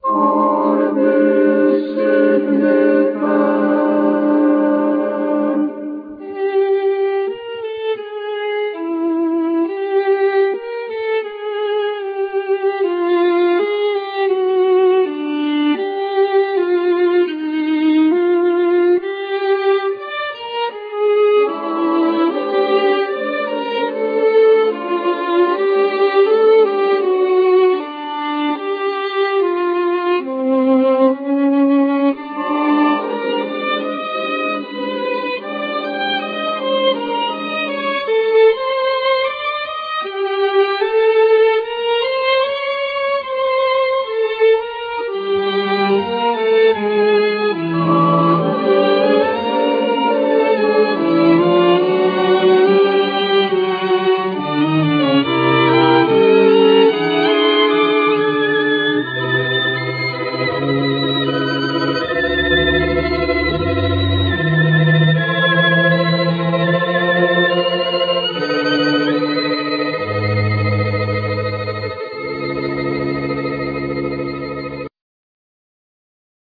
Flute,Alt flute,Alt sax, Marimba, Drums, Percussion, etc
Piano, El.piano, Cembalo
Chorus
Strings Quartet(1st Violin, 2nd Violin, Viola, Cello)